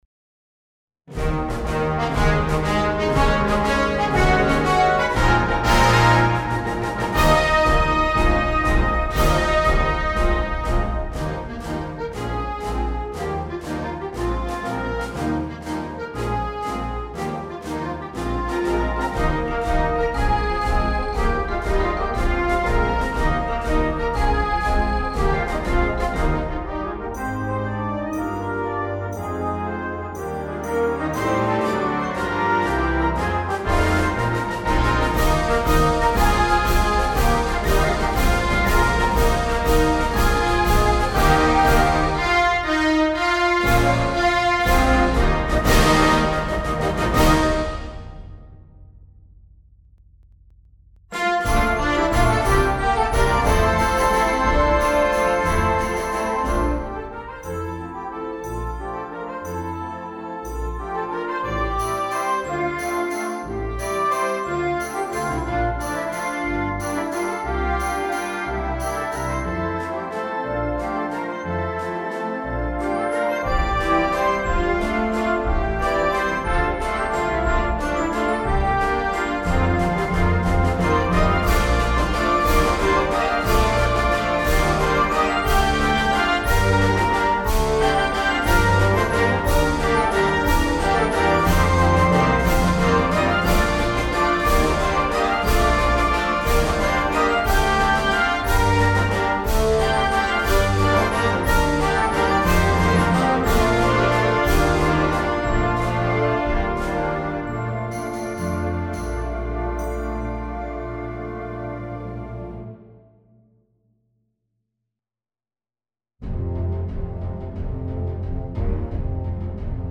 Partitions pour orchestre d'harmonie.
un andante expressif et mélancolique